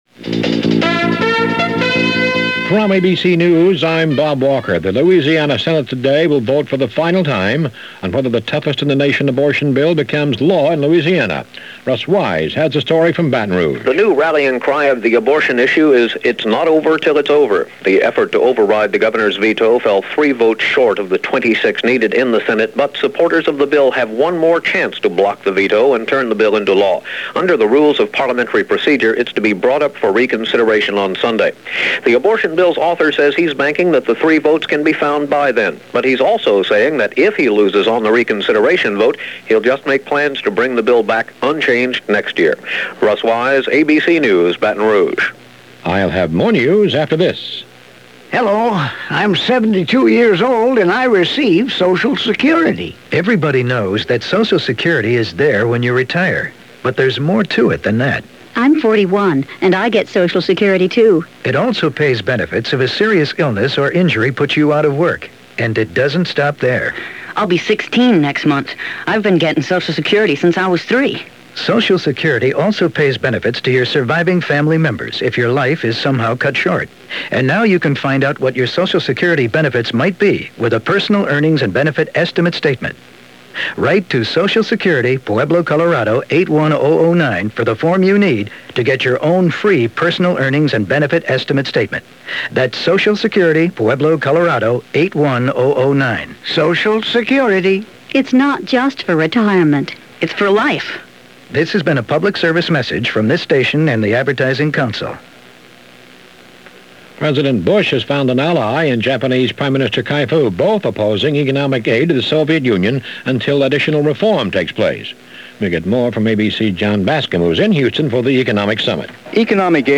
And that’s a small slice of what went on this July 8th in 1990 as reported by ABC Information Network News.